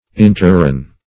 Search Result for " interreign" : The Collaborative International Dictionary of English v.0.48: Interreign \In"ter*reign`\, n. [Cf. F. interr[`e]gne.] An interregnum.